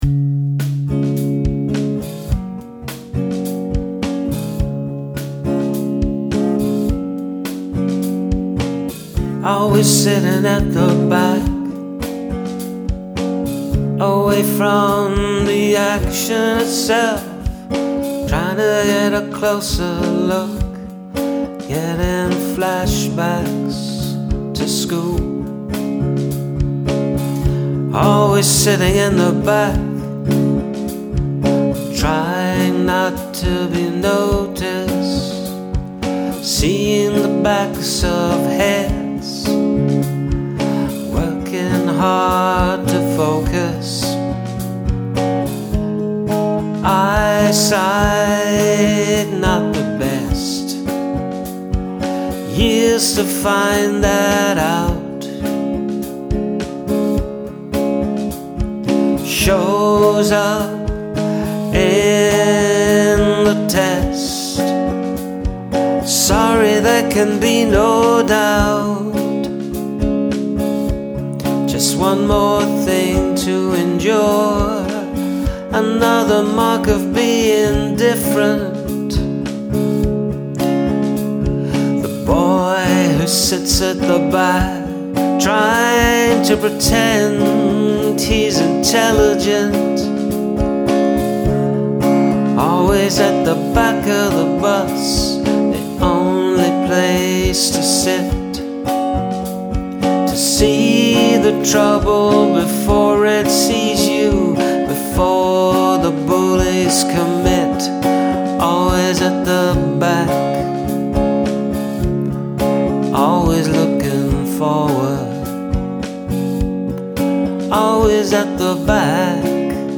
A bit somber, but not depressing.